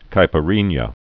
(kīpə-rēnyə)